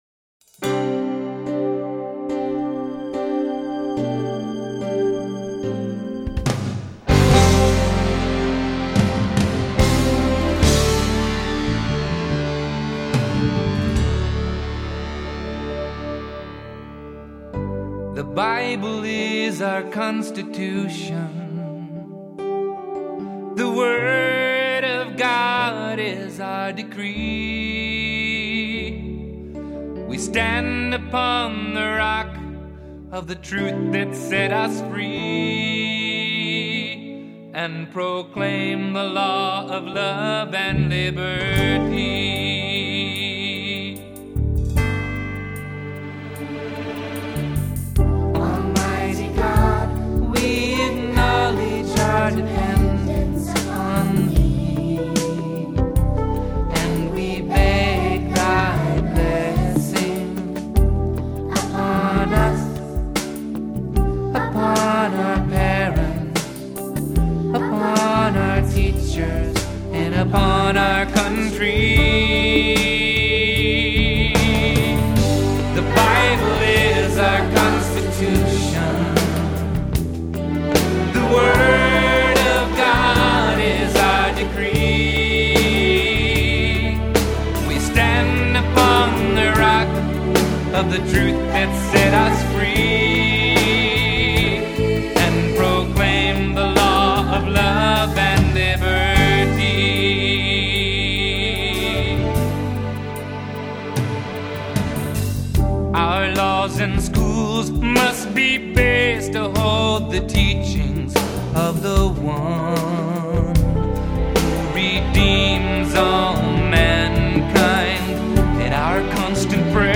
Praise & Worship